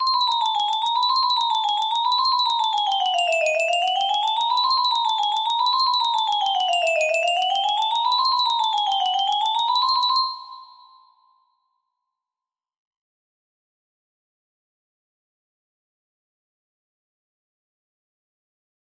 Clone of Unknown Artist - Untitled music box melody